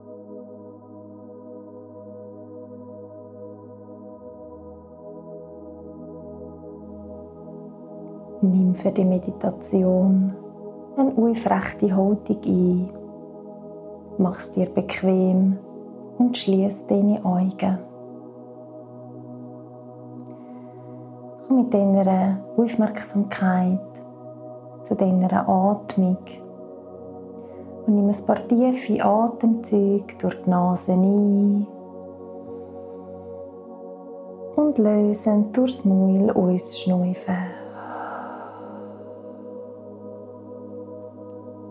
Geführte Meditation in SchweizerdeutschAls Mp3 zum Download
Hoerprobe-FEEL-YOU-Meditation-1.mp3